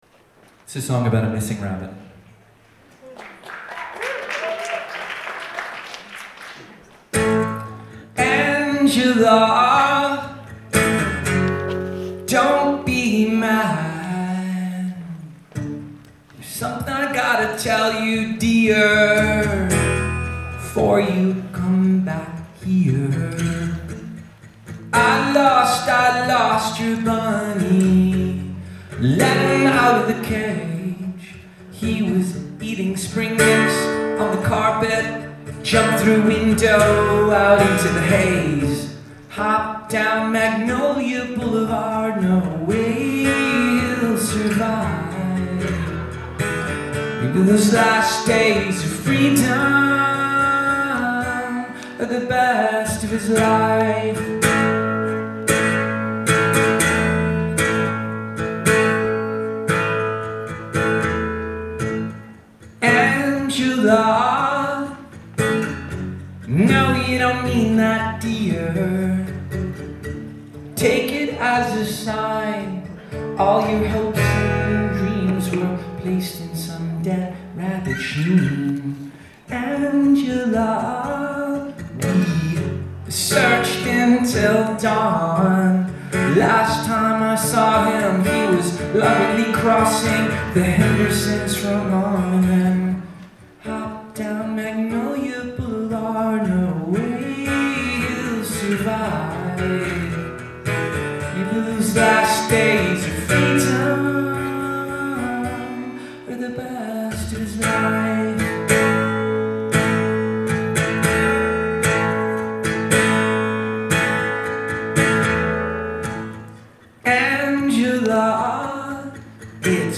Live at the Somerville Theatre